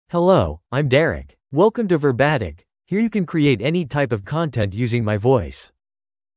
MaleEnglish (United States)
DerekMale English AI voice
Derek is a male AI voice for English (United States).
Voice sample
Male
Derek delivers clear pronunciation with authentic United States English intonation, making your content sound professionally produced.